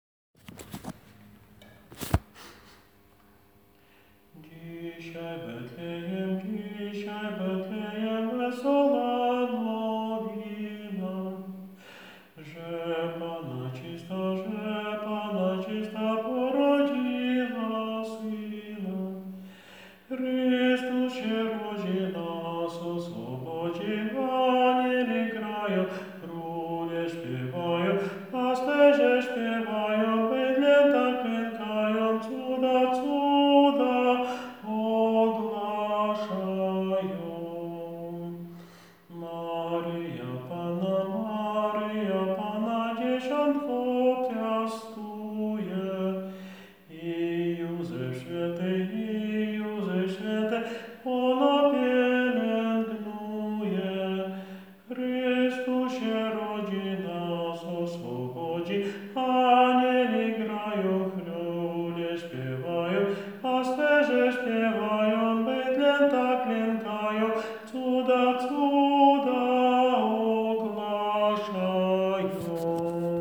Dzisiaj w Betlejem Sopran 1 - nagranie utworu z głosem nauczyciela ze słowami a capella (bez pomocy instrumentu)